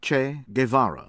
Ernesto Guevara [erˈnesto geˈβaɾa] (Rosario, Argentína, 1928. június 14.[1]La Higuera, Bolívia, 1967. október 9.), ismertebb nevén Che Guevara ([ˈtʃe ɣeˈβaɾa]